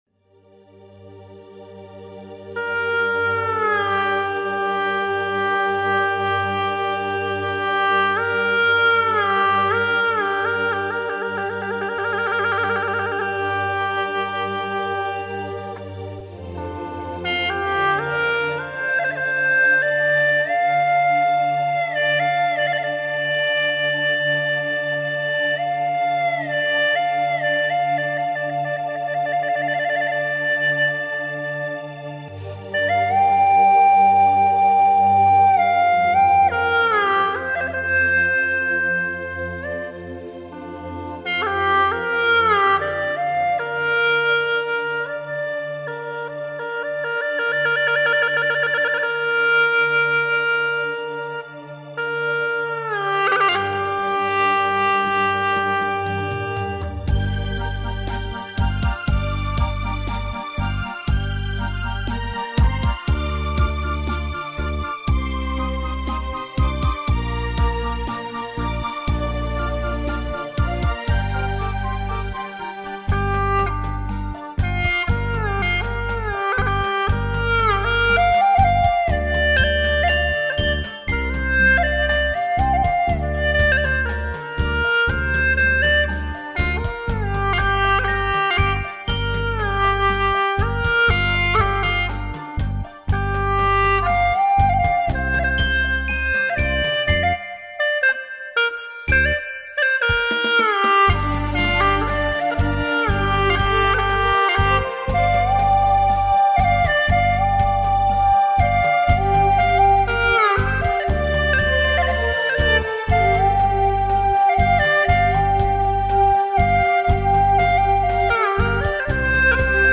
调式 : 降B 曲类 : 独奏
作品意境既写景又写人，有柔美抒情，又有欢快跳跃。